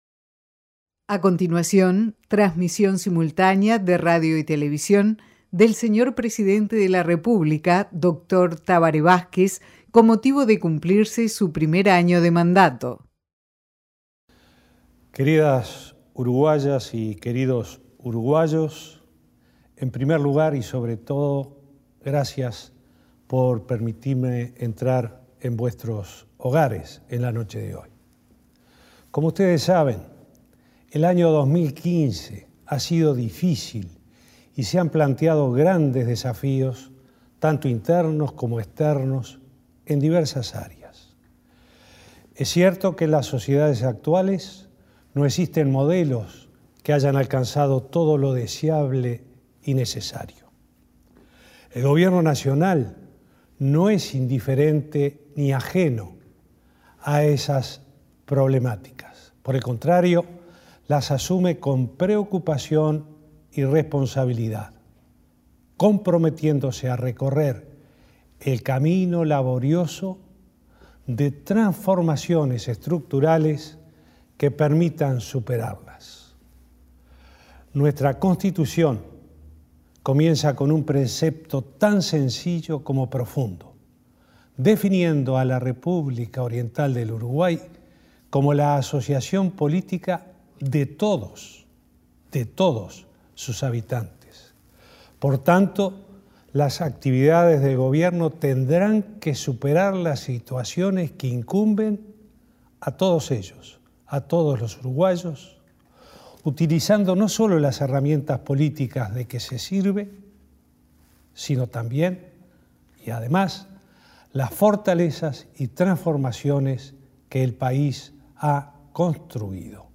El presidente Tabaré Vázquez dijo este martes en un mensaje emitido por radio y televisión a todo el país que mantener el grado inversor y luchar contra la inflación serán dos de los principales objetivos de su gobierno en 2016.
Discurso de Tabaré Vázquez